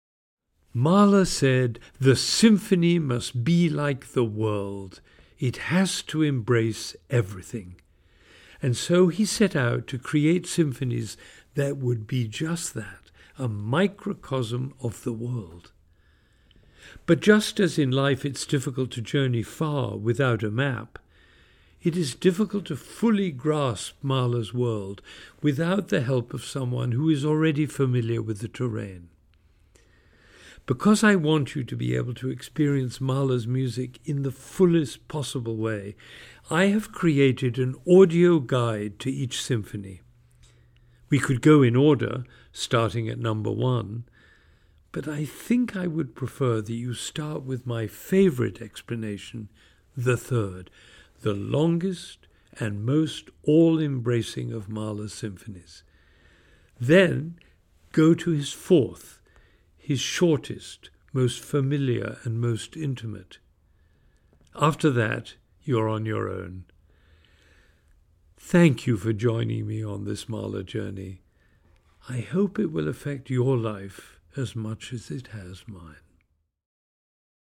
Click below to listen to Ben’s introduction the works of Gustav Mahler.